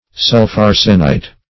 Sulpharsenite \Sulph*ar"se*nite\, n.